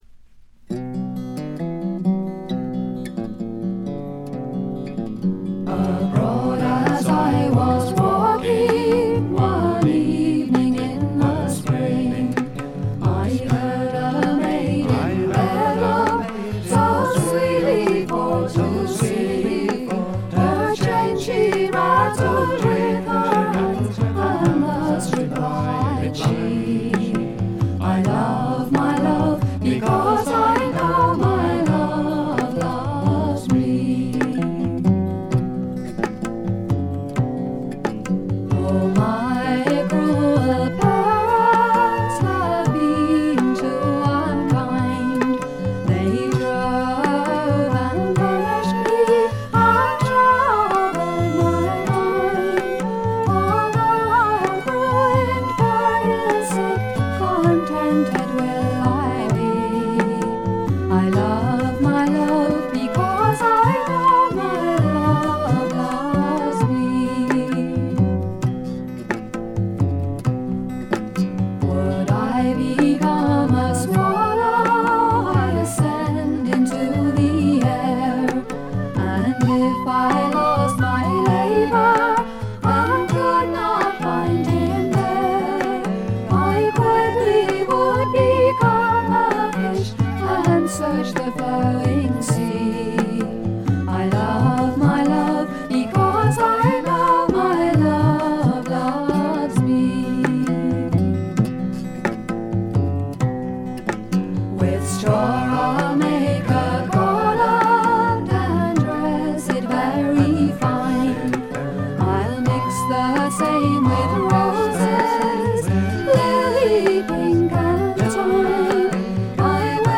部分試聴ですがほとんどノイズ感無し。
また専任のタブラ奏者がいるのも驚きで、全編に鳴り響くタブラの音色が得も言われぬ独特の味わいを醸しだしています。
試聴曲は現品からの取り込み音源です。
vocals, flute, recorders, oboe, piccolo
fiddle, vocals
tabla, finger cymbals